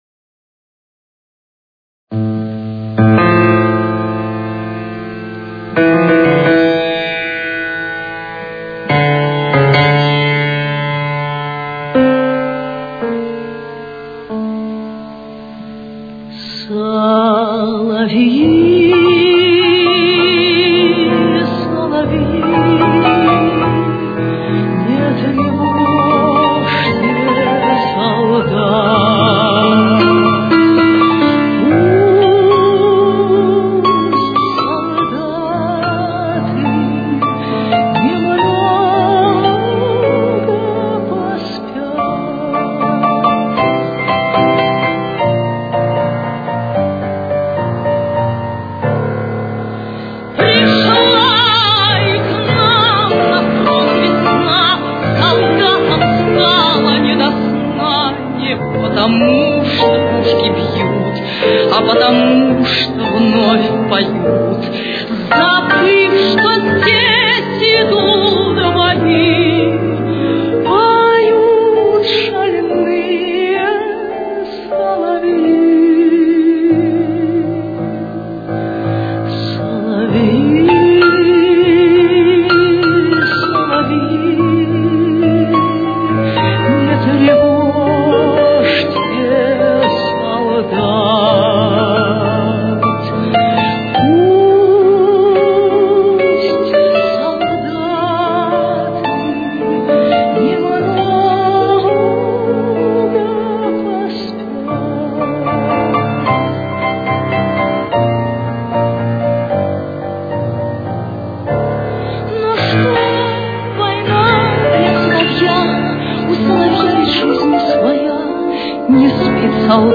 Ре минор.